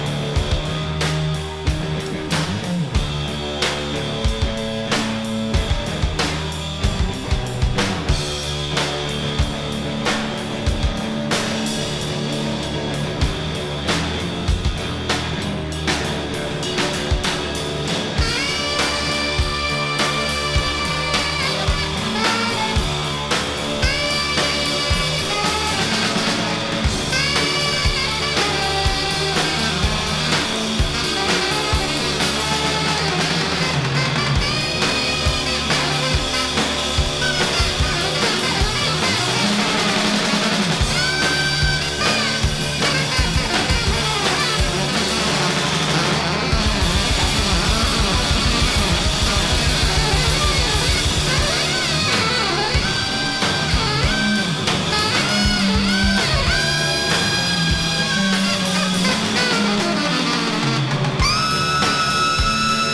GrindcoreとFree Jazzが混在した凄まじいサウンド！